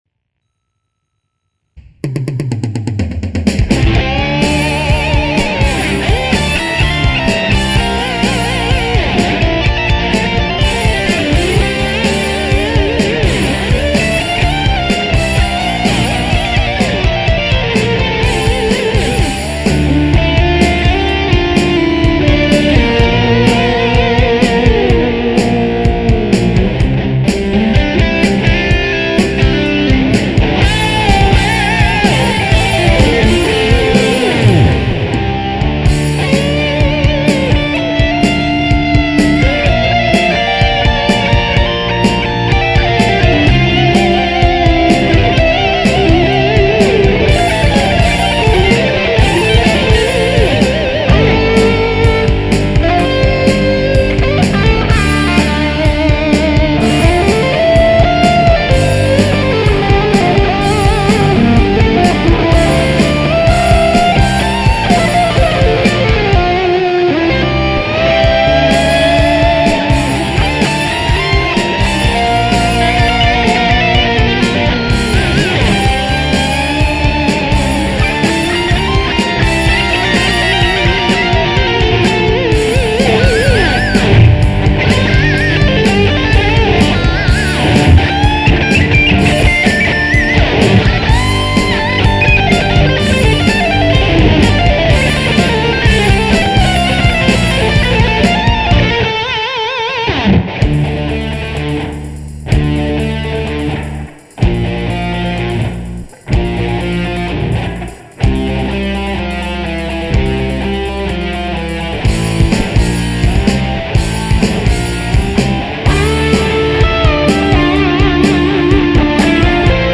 All Rhythm Tracks..Main Solo at 1:58 - 2:36